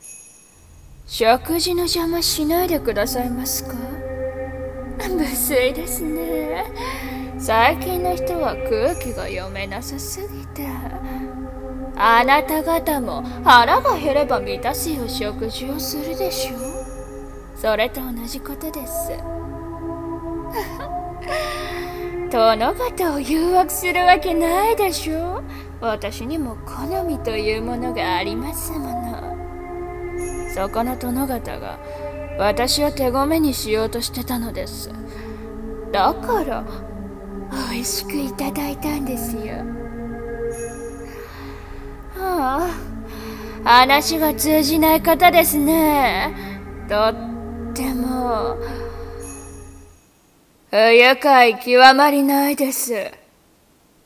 1人声劇『退魔の夜-魔魅(まみ)-』